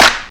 Index of /90_sSampleCDs/Techno_Trance_Essentials/DRUMS/CLAP
40_21_clap.wav